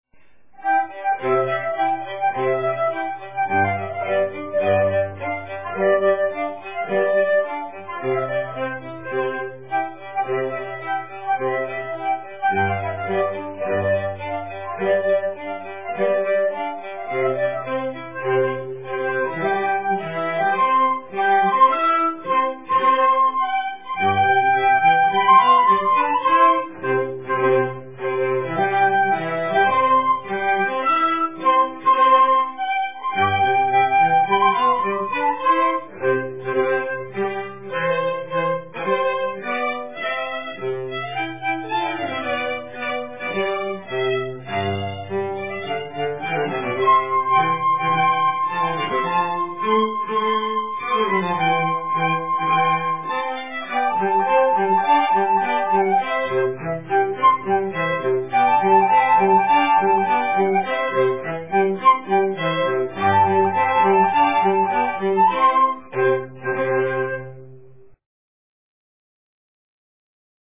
Country Dance #1